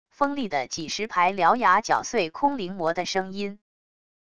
锋利的几十排獠牙绞碎空灵魔的声音wav音频